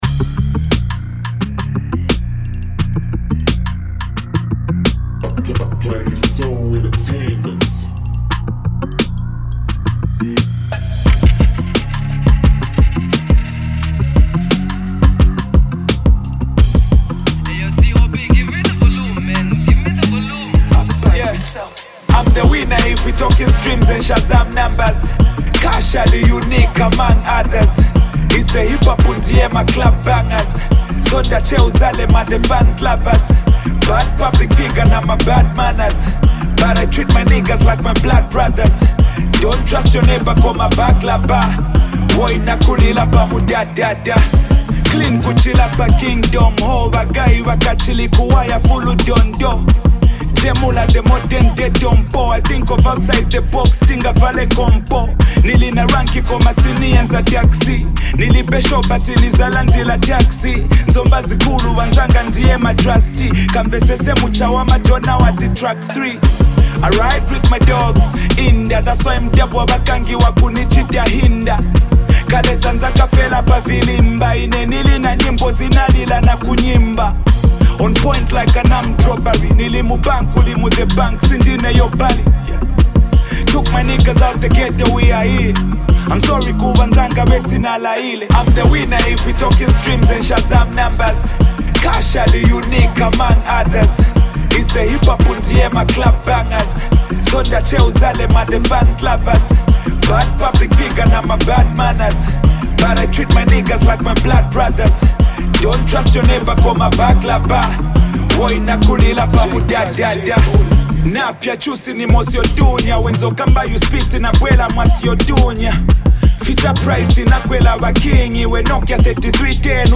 razor-sharp bars